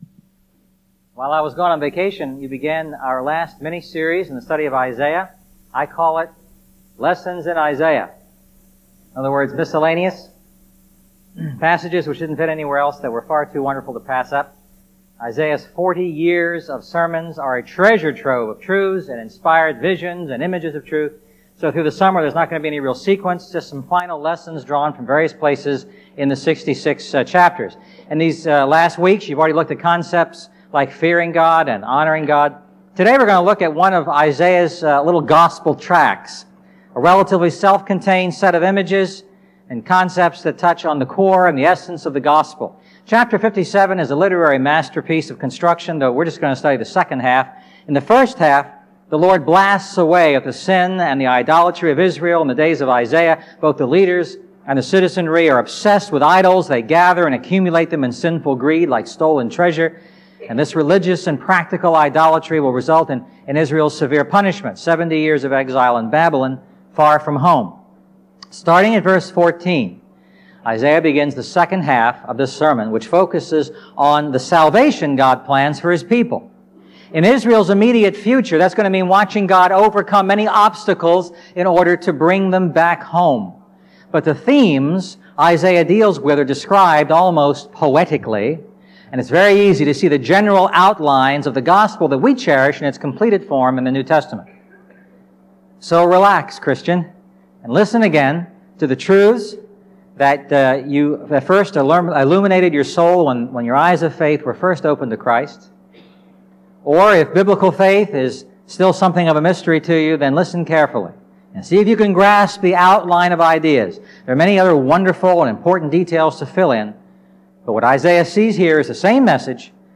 A message from the series "The Lord Saves."